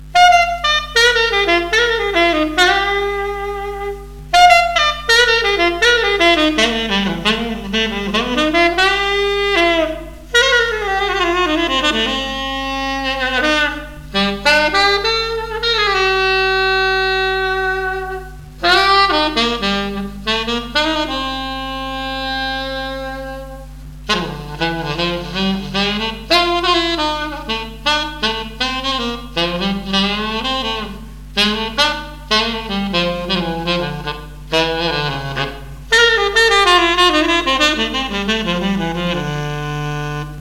Vintage 1925 Buescher 'Truetone' C-Melody Sax - Bare-Brass !!
It is very easy to play - I use tenor sax mouthpieces (more details below) rather than the original slightly more 'reserved' mouthpieces -  they can produce quite a contemporary blues/rock/jazz sound, very easy-blowing, and with the typical Buescher 'big sound', easily going from a whisper to a roar.
I've recorded a very basic 'doodling' sound sample on the sax  ( direct into the PC mic - so not exactly 'studio quality' ) using my own tenor mouthpiece.
Hear the lyrical top, plus the beefy tenor'ish bottom, alto and tenor in one horn !